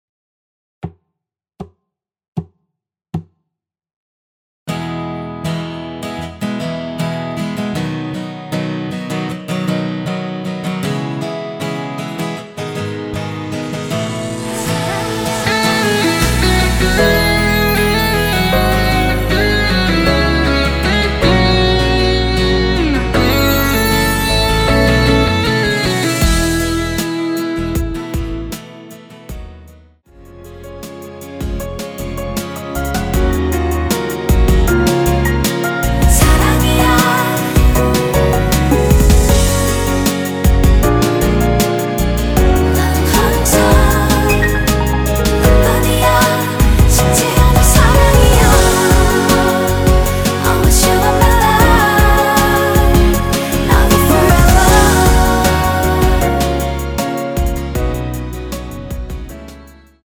전주 없이 시작하는 곡이라서 노래하기 편하게 카운트 4박 넣었습니다.(미리듣기 확인)
원키 코러스 포함된 MR입니다.
앞부분30초, 뒷부분30초씩 편집해서 올려 드리고 있습니다.